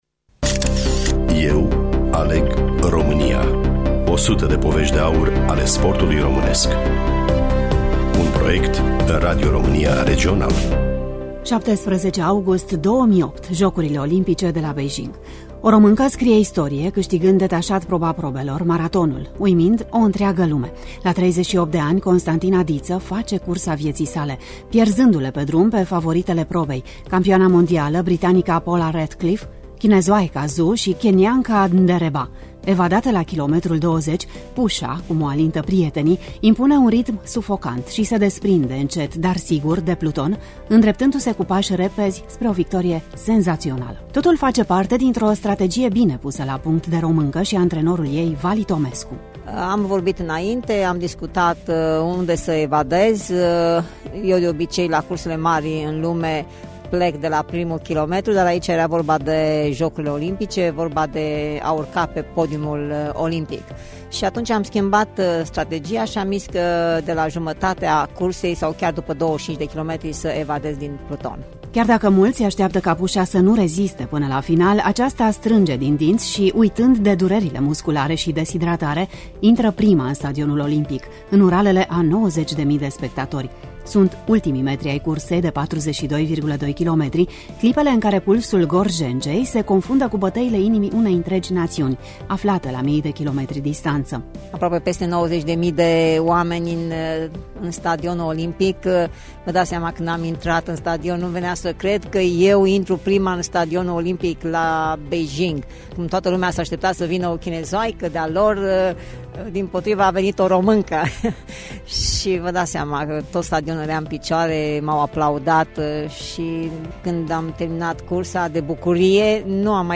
Studioul: Radio România Tg.Mureş